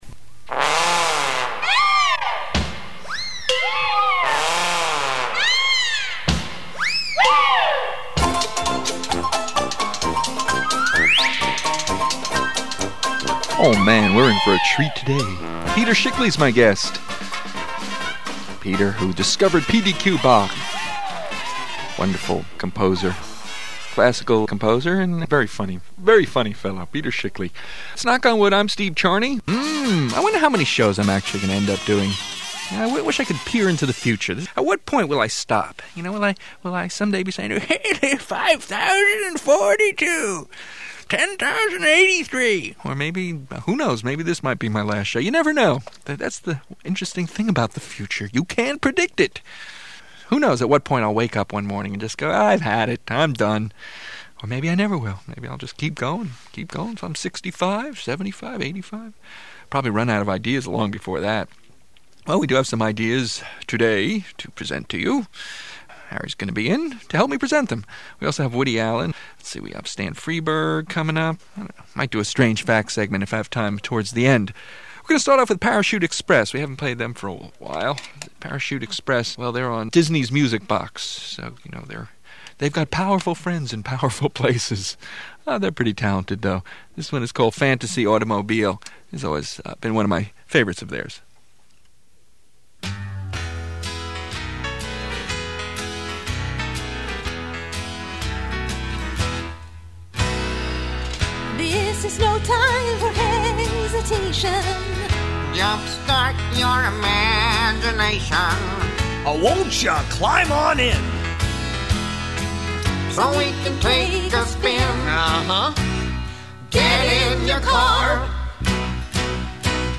Comedy Show
Peter Schickele is a guest.